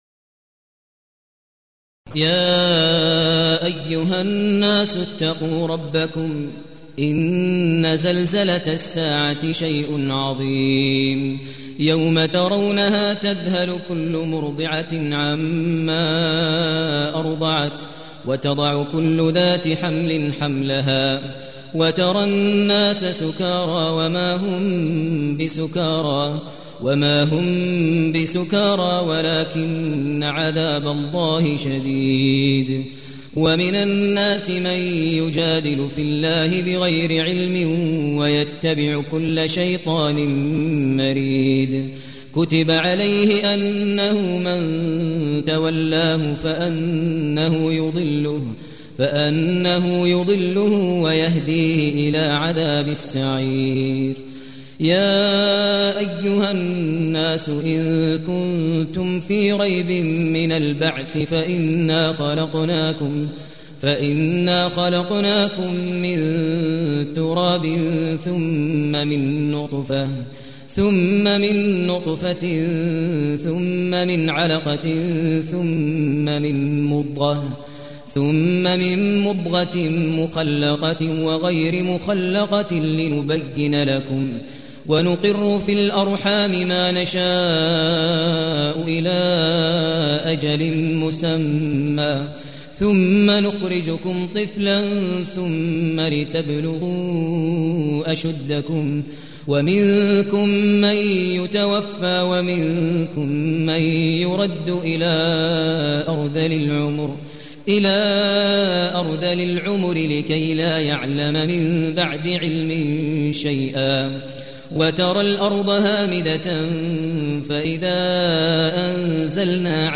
المكان: المسجد النبوي الشيخ: فضيلة الشيخ ماهر المعيقلي فضيلة الشيخ ماهر المعيقلي الحج